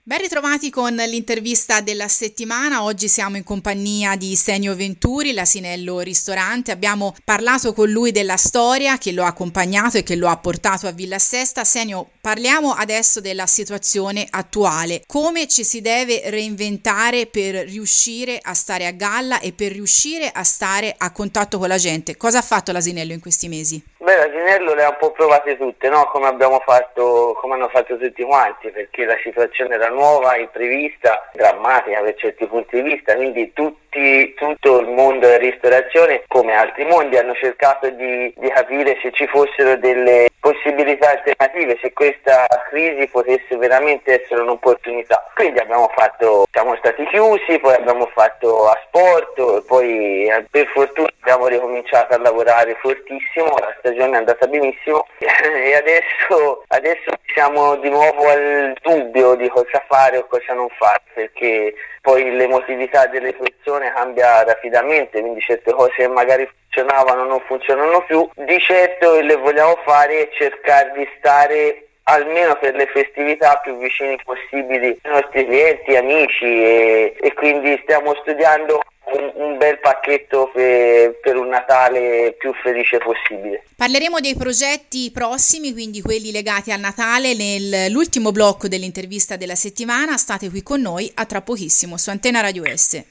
Sotto l’intervista completa.